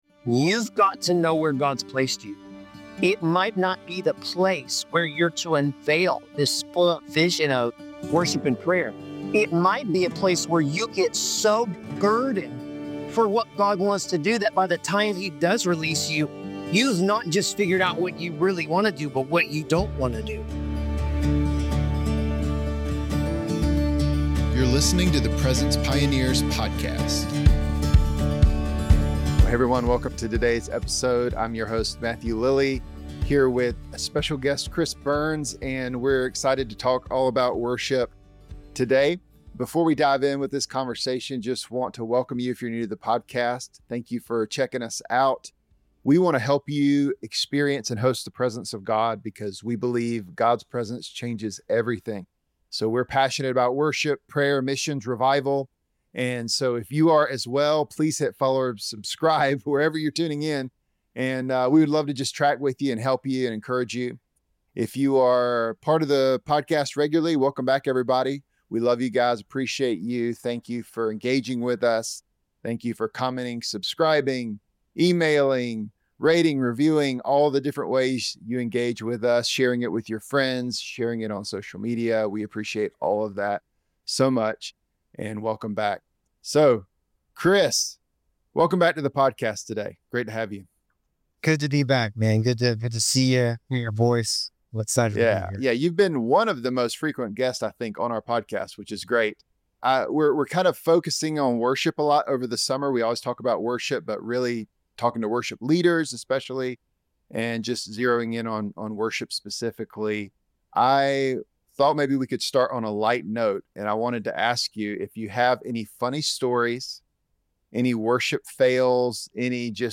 In this engaging conversation